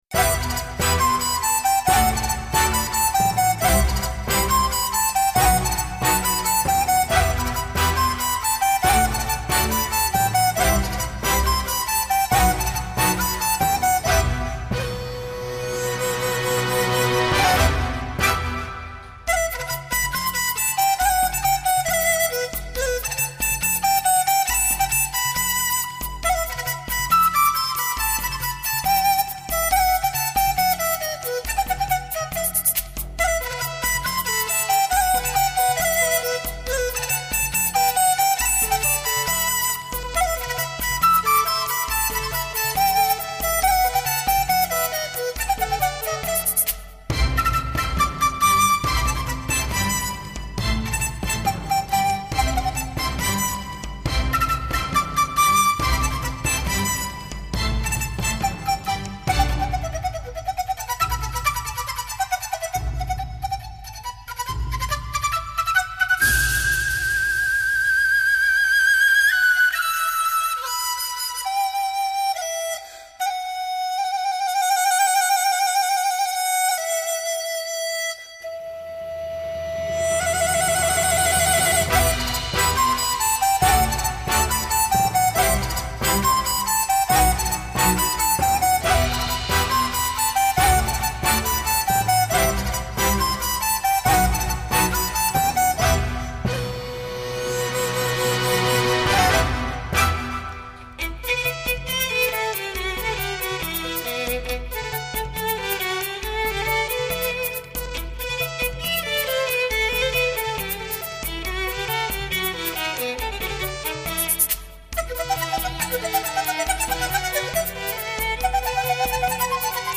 水晶笛
风格独特，气息敦厚稳健，音色圆润甜美，清脆响亮，自然流畅
华丽时光彩夺目，细致时玲珑剔透，情绪交替犹如行云流水